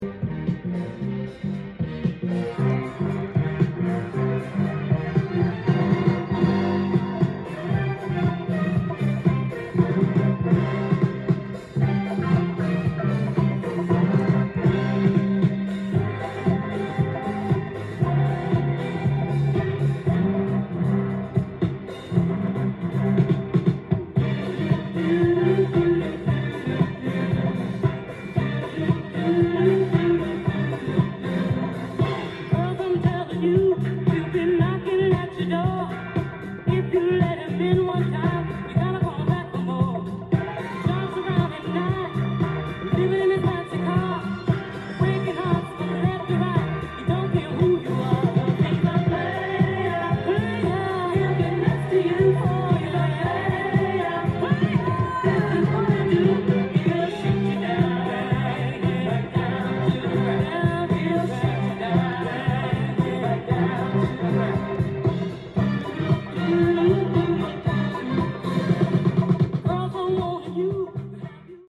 ジャンル：Soul-7inch-全商品250円
店頭で録音した音源の為、多少の外部音や音質の悪さはございますが、サンプルとしてご視聴ください。